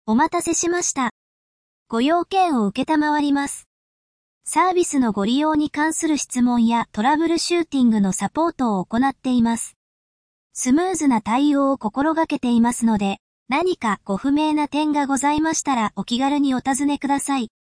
AI音声合成・音声読み上げ（WEB テキスト）ソフトのReadSpeaker（リードスピーカー）